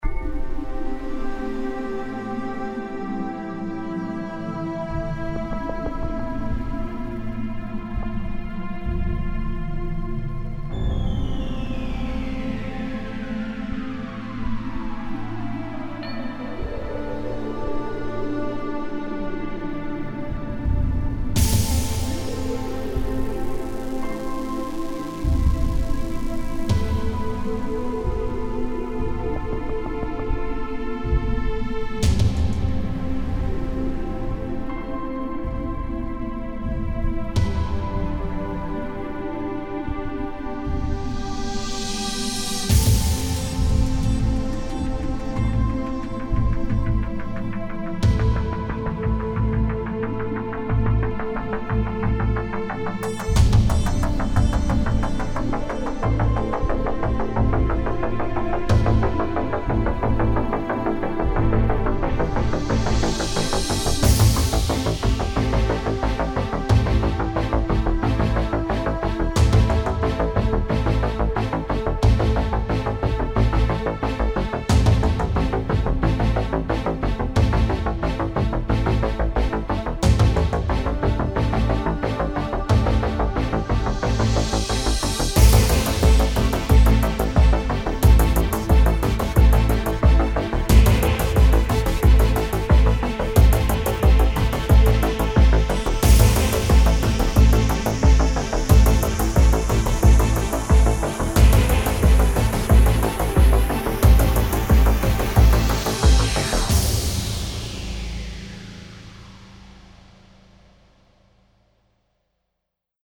来自另一个世界的下一代科幻声音和音轨。
此免版税的声音包总共包含15条音轨，这些音轨也分为各自的部分（鼓，旋律，fx，打击，合唱等等）。